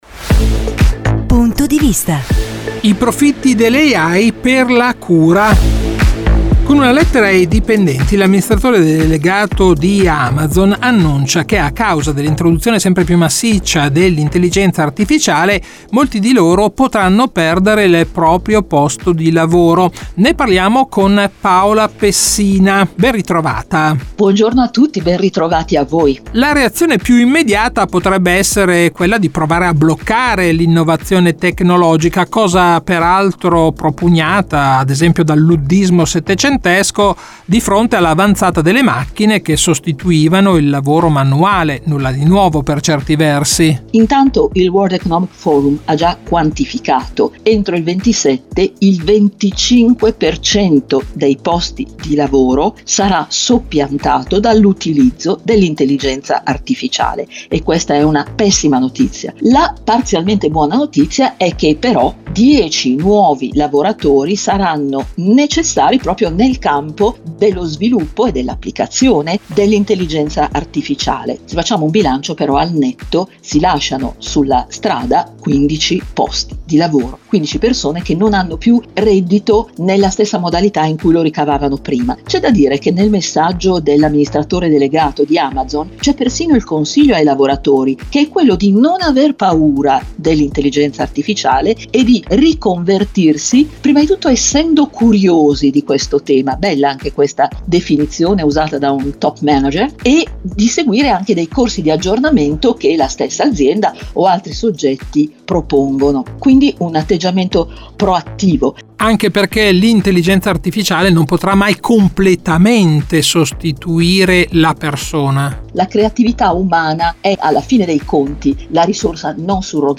un dialogo su come ridistribuire le risorse dell’IA per valorizzare il lavoro di cura e il benessere collettivo.